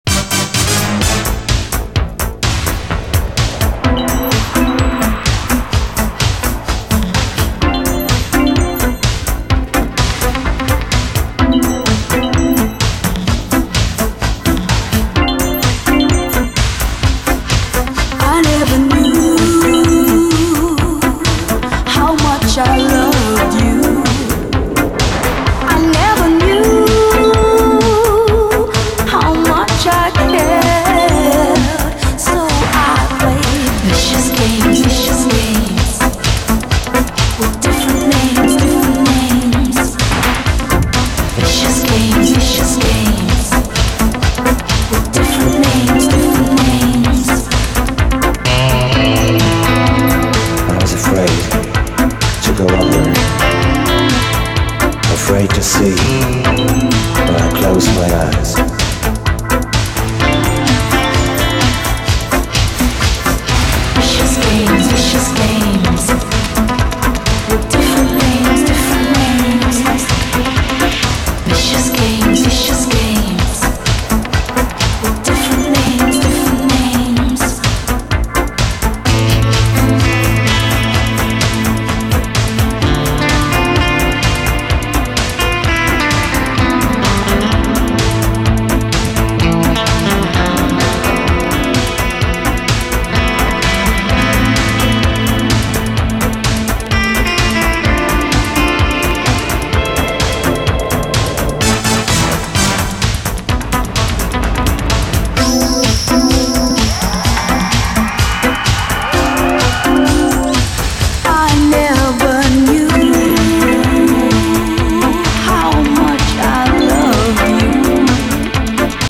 DISCO, 7INCH
変態的な妖しさのダーク・ニューウェーヴ・ディスコ！